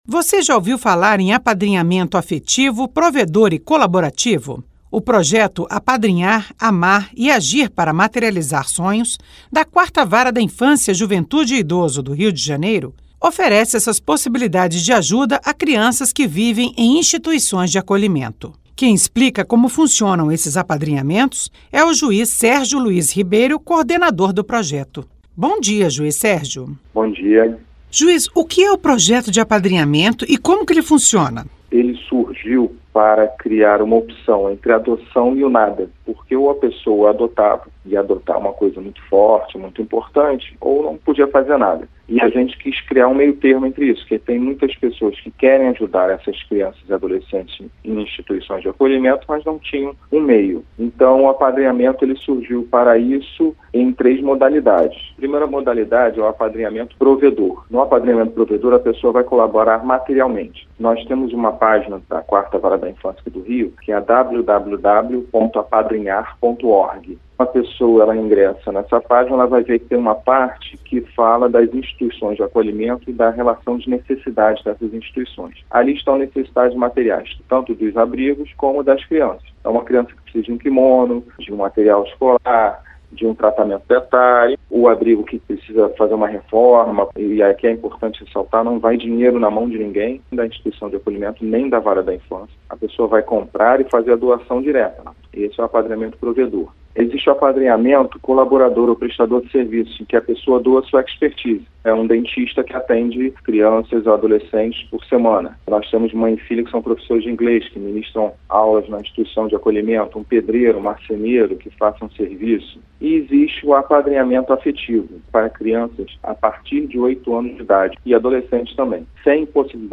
Juiz explica modalidades de apadrinhamento de crianças e adolescentes na cidade do Rio de Janeiro